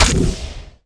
gigoong_impact.wav